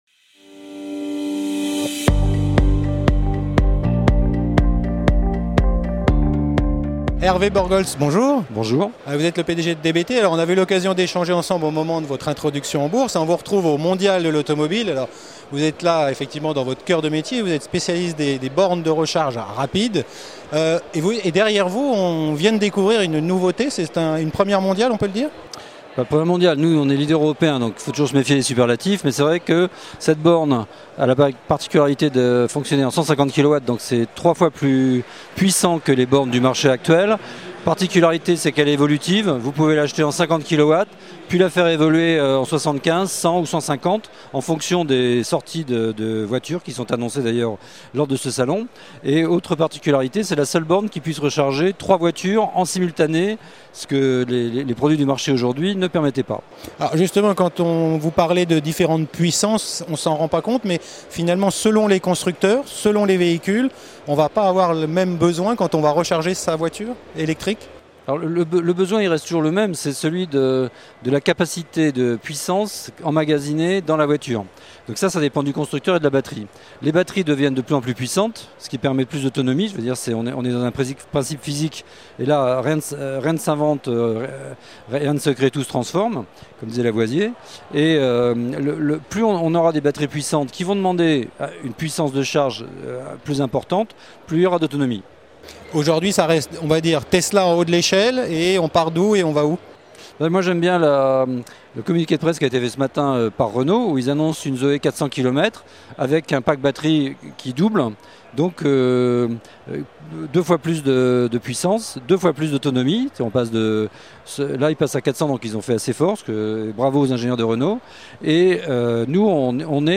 La Web TV présente au Mondial de l’Automobile 2016 à la rencontre des constructeurs automobiles et des équipementiers.
Category: L'INTERVIEW